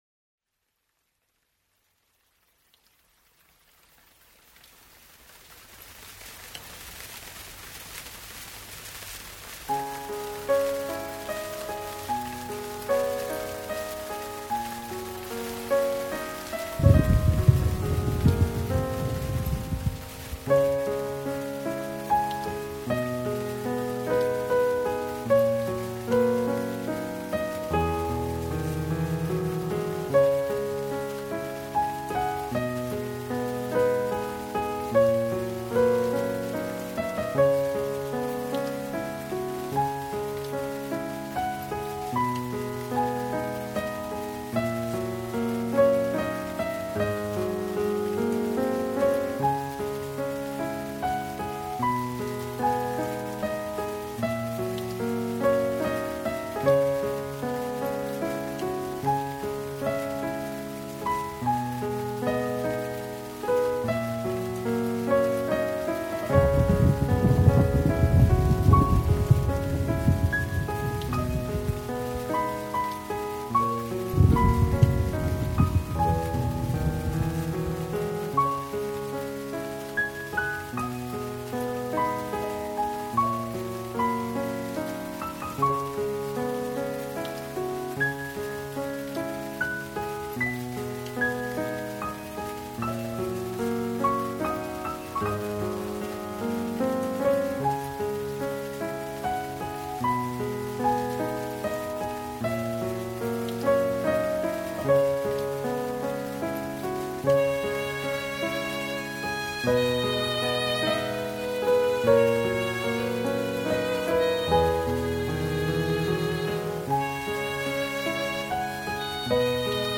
（夜雨/钢琴） 激动社区，陪你一起慢慢变老！